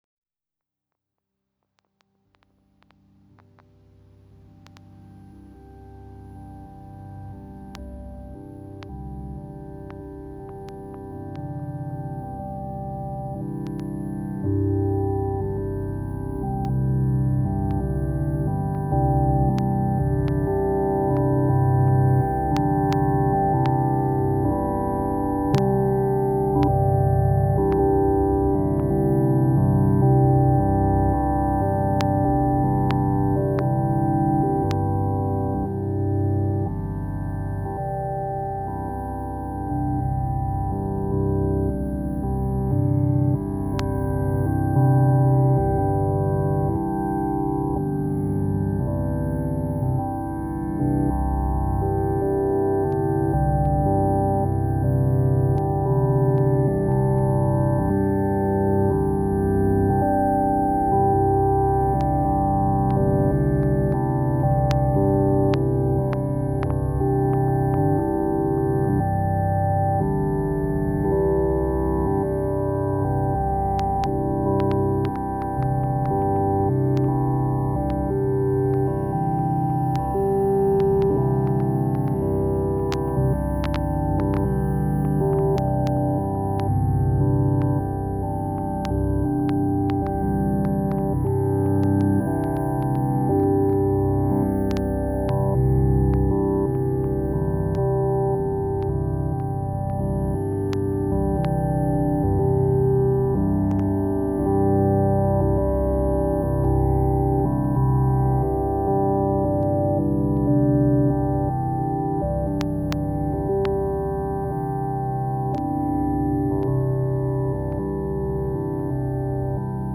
experimental music